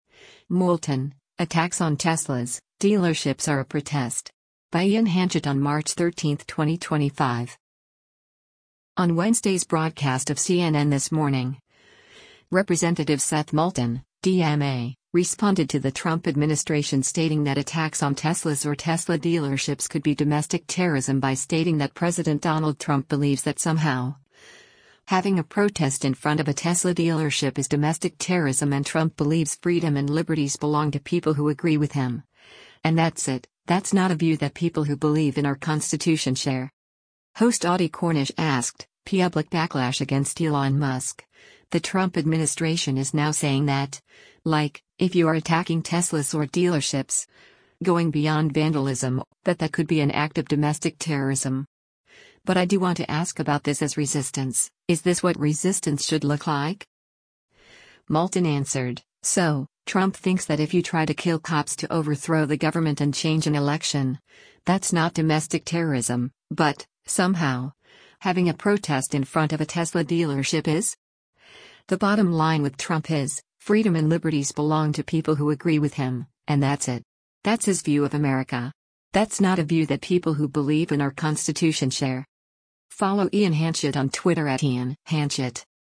On Wednesday’s broadcast of “CNN This Morning,” Rep. Seth Moulton (D-MA) responded to the Trump administration stating that attacks on Teslas or Tesla dealerships could be domestic terrorism by stating that President Donald Trump believes that “somehow, having a protest in front of a Tesla dealership is” domestic terrorism and Trump believes “freedom and liberties belong to people who agree with him, and that’s it. … That’s not a view that people who believe in our Constitution share.”